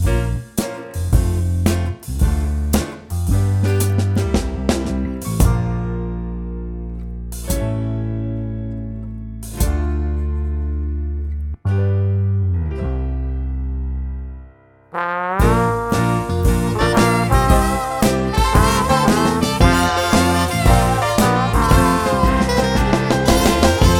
Minus Electric Guitar Pop (1980s) 4:10 Buy £1.50